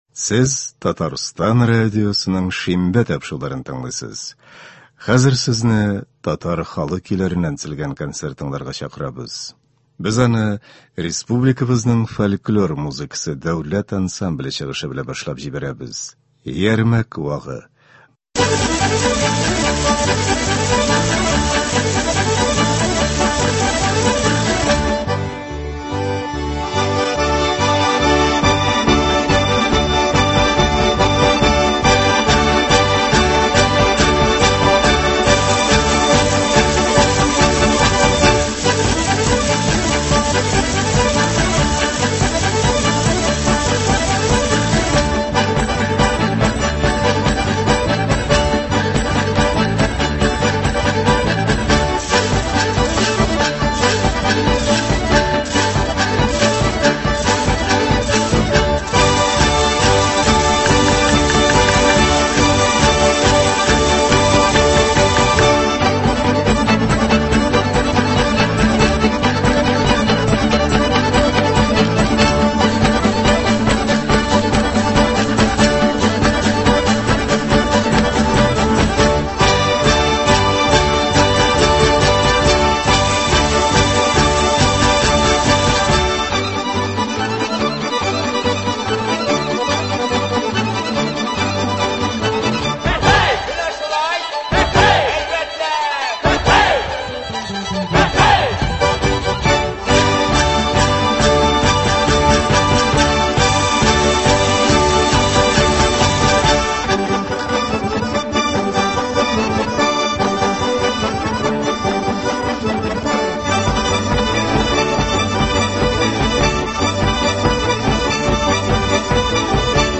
Татар халык көйләре (23.09.23)